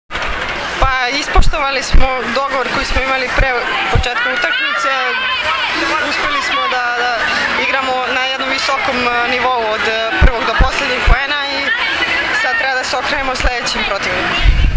IZJAVA SUZANE ĆEBIĆ